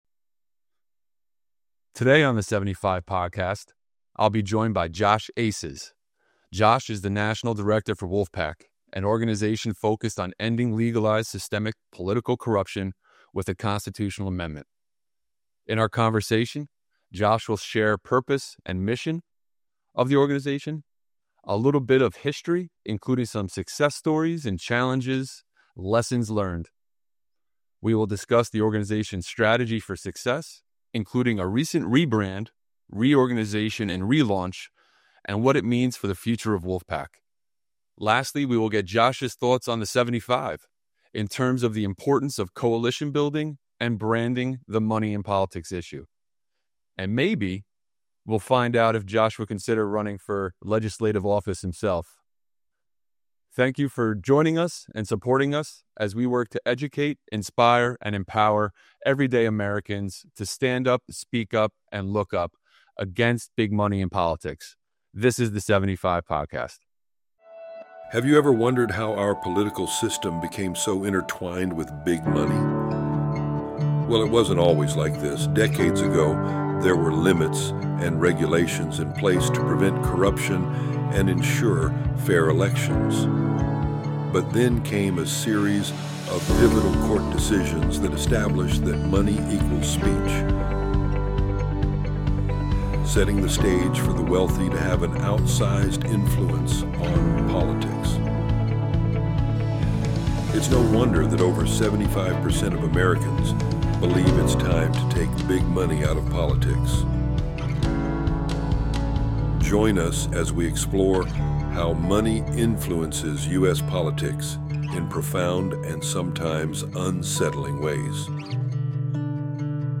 In this in-depth interview